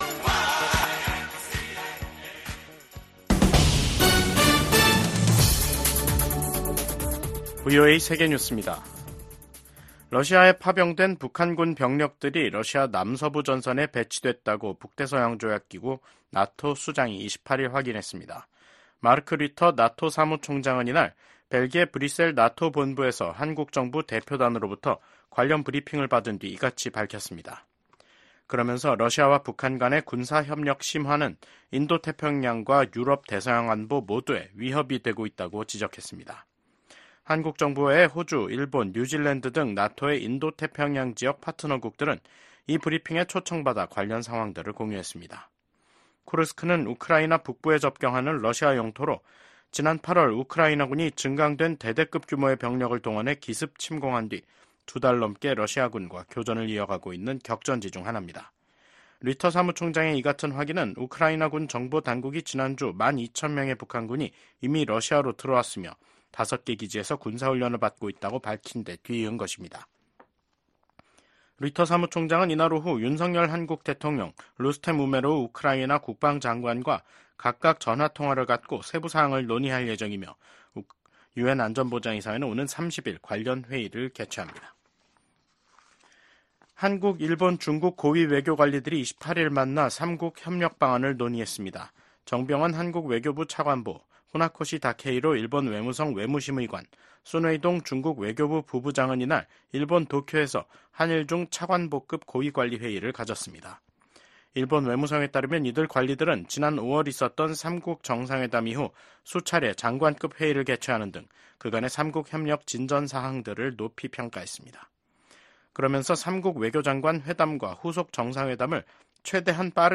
VOA 한국어 간판 뉴스 프로그램 '뉴스 투데이', 2024년 10월 28일 3부 방송입니다. 러시아에 파견된 북한군의 역할에 관심이 집중되고 있는 가운데 미한외교 안보 수장이 워싱턴에서 ‘2+2회담’을 갖고 해당 현안을 논의합니다. 미국과 한국, 일본의 안보 수장들이 워싱턴에서 만나 북한군의 러시아 파병에 깊은 우려를 나타냈습니다.